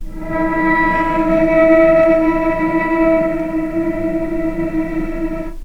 healing-soundscapes/Sound Banks/HSS_OP_Pack/Strings/cello/sul-ponticello/vc_sp-E4-pp.AIF at b3491bb4d8ce6d21e289ff40adc3c6f654cc89a0
vc_sp-E4-pp.AIF